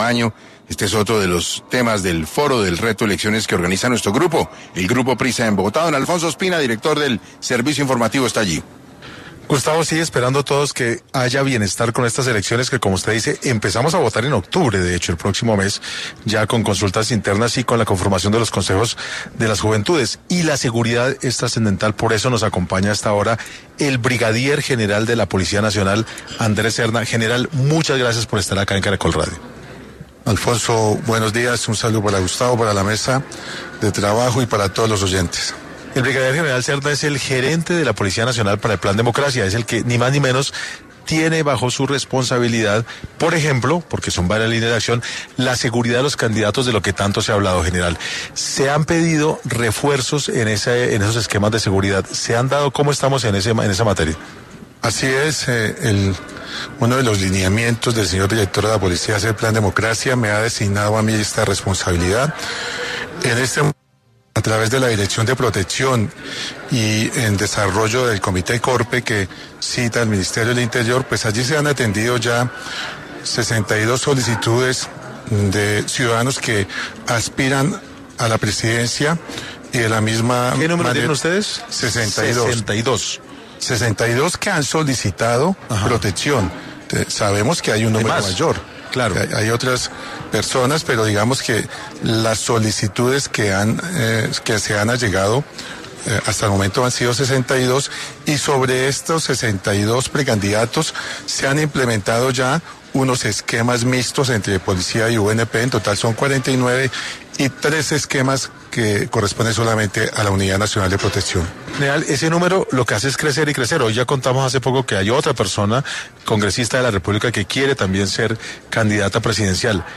En el marco del foro Reto Elecciones organizado por el Grupo Prisa en Bogotá, el brigadier general Andrés Serna, quien es el gerente del Plan Democracia de la Policía Nacional, explicó las medidas que se están adoptando para garantizar la seguridad de los candidatos y del proceso electoral que inicia con consultas internas y elecciones de los Consejos de Juventud.
Durante la entrevista, Serna también agradeció las expresiones de apoyo de los oyentes y ratificó la misión de la institución.